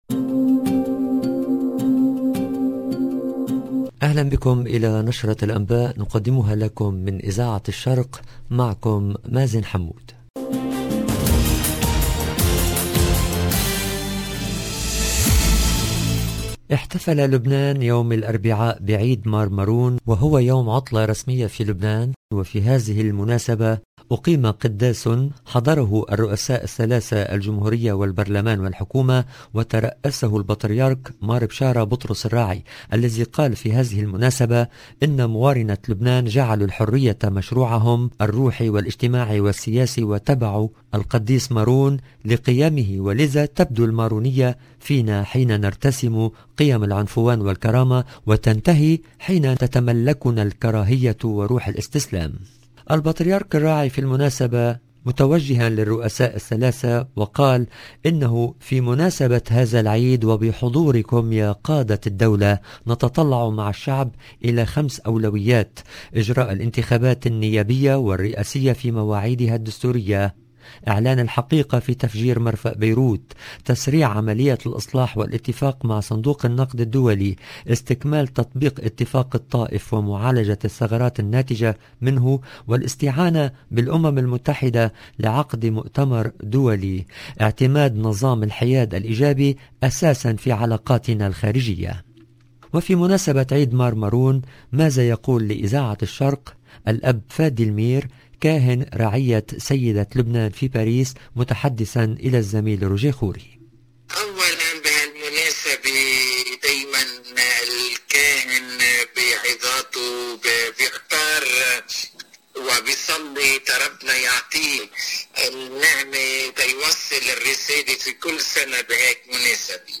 LE JOURNAL DU SOIR EN LANGUE ARABE DU 9/02/22
مقابلة حول المناسبة